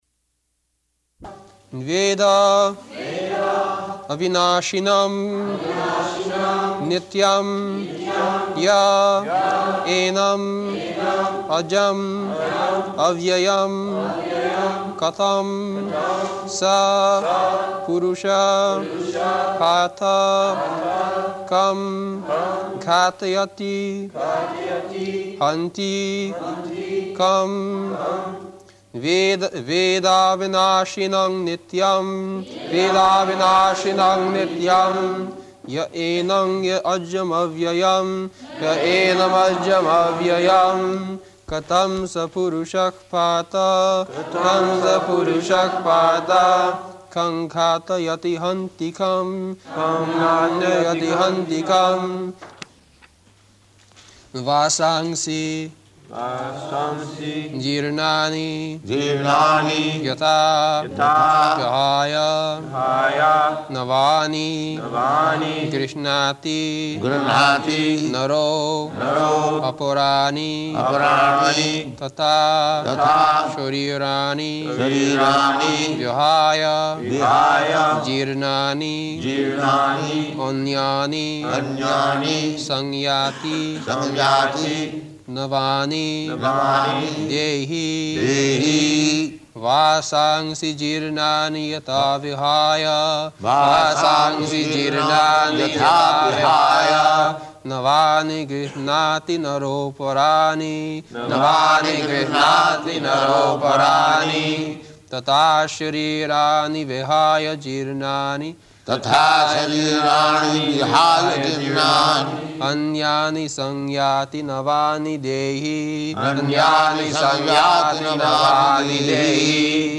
Location: London
[Prabhupāda and devotees repeat]